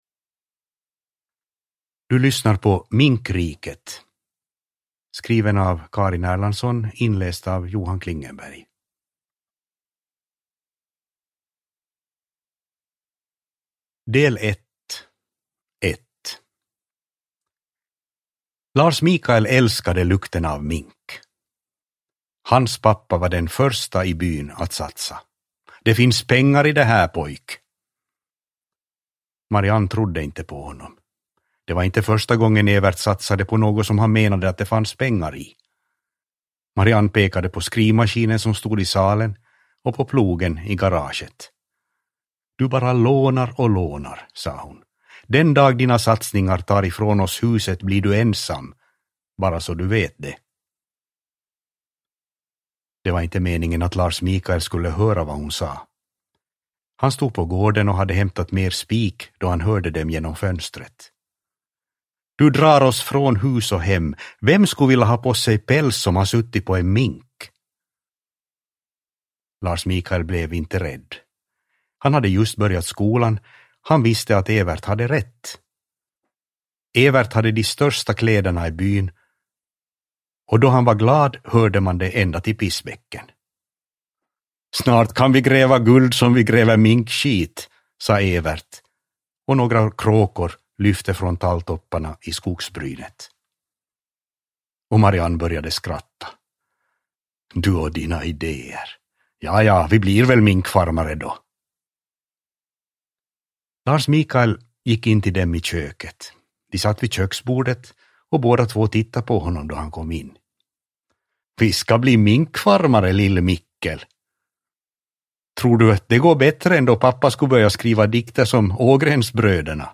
Minkriket – Ljudbok – Laddas ner